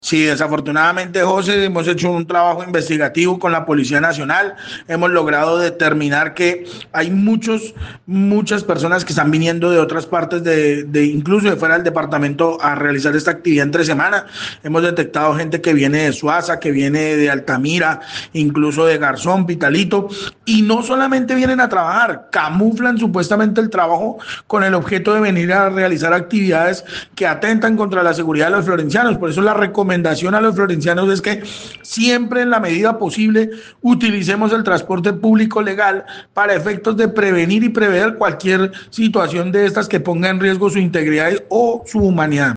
Jaime Becerra, secretario de transporte y movilidad en la ciudad de Florencia, dijo que junto a la Policía se han detectado mototaxistas provenientes de municipios del sur del Huila como Suaza, Altamira, Garzón y Pitalito, además de otras localidades del Caquetá.